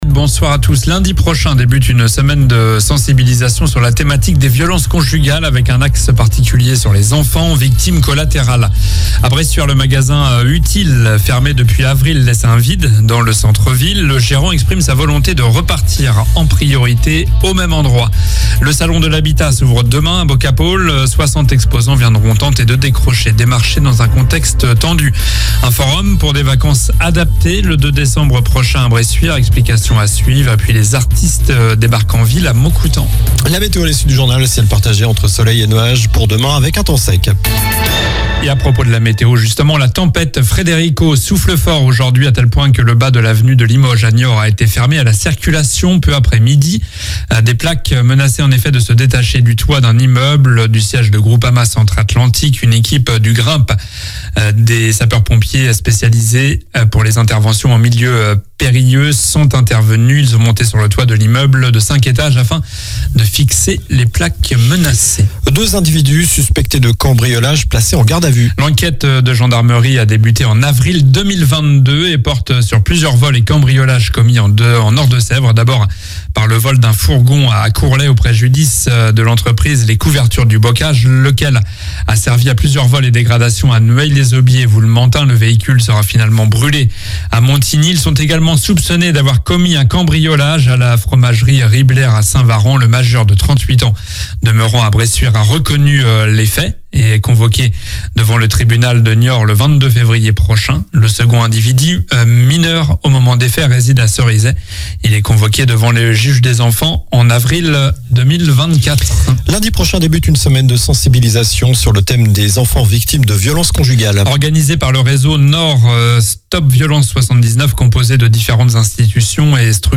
Journal du jeudi 16 novembre (soir)